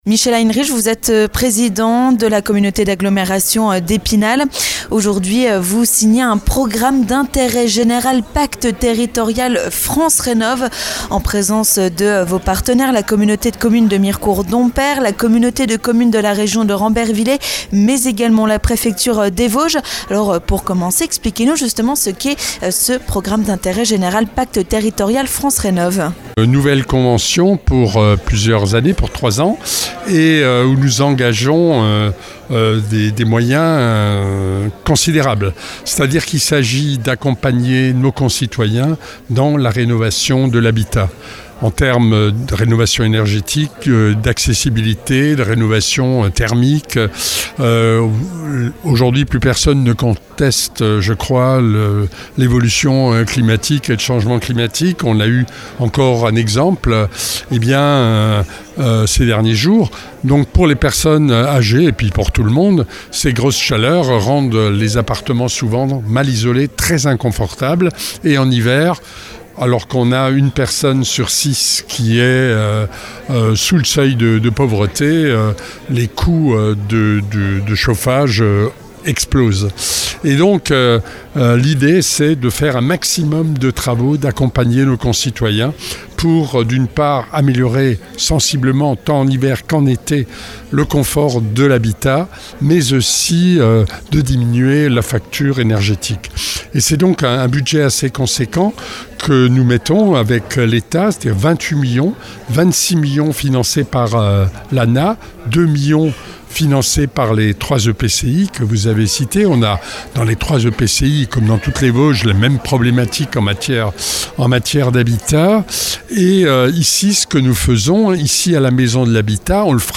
Michel Heinrich, président de la Communauté d'Agglomération d'Epinal nous en dit plus sur ce dispositif.